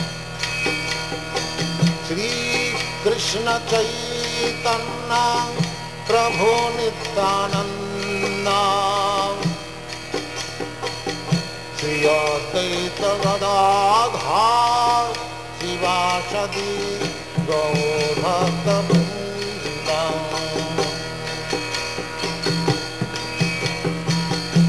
Presione aquí y escuche el pancatttwa maha-mantra (wav) cantado por Srila Bhaktivedanta Swami Prabhupada